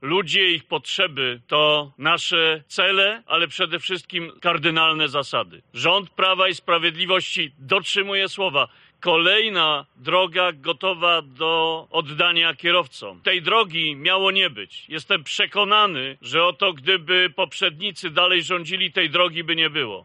O inwestycji mówił podczas uroczystego otwarcia, Andrzej Adamczyk, Minister Infrastruktury: